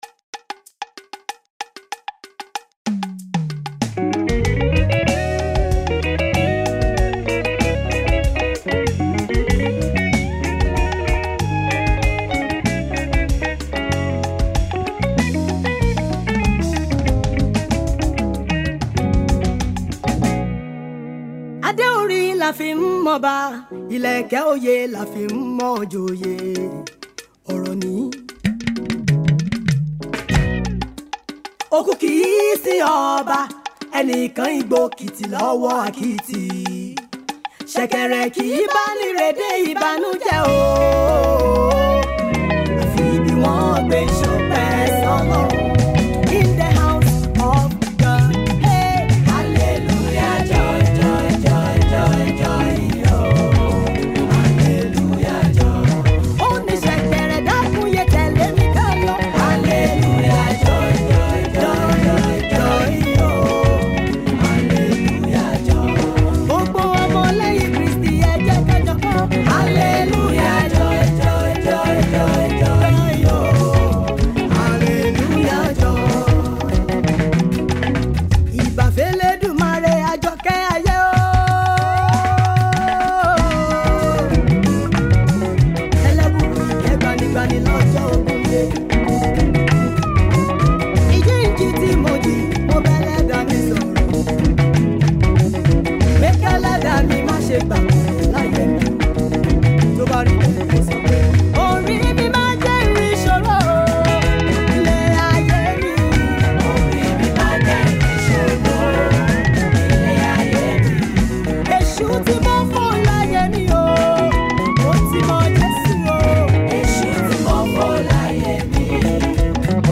Gospel Chanter